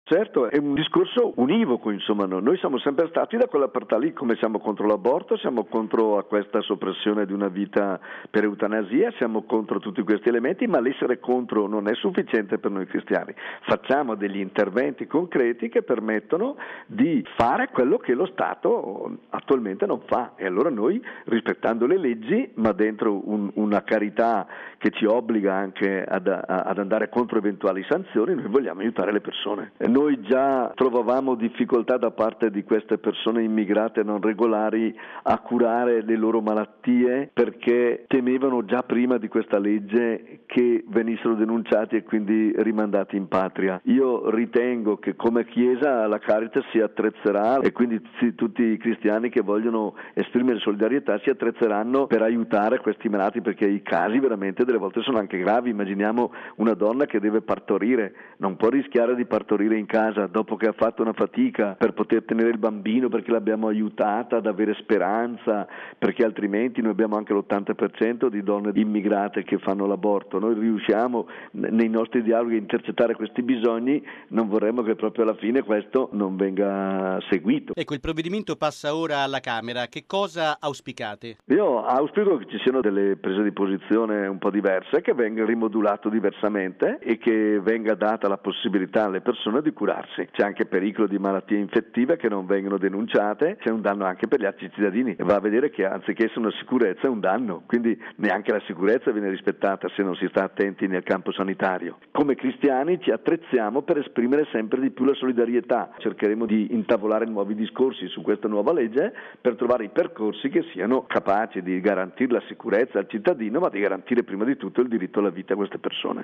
Il segretario della Commissione episcopale per le migrazioni della Cei, mons. Domenico Sigalini, vescovo di Palestrina, ha detto che il sì della Chiesa alla vita per Eluana va di pari passo al sì al rispetto della vita degli immigrati irregolari.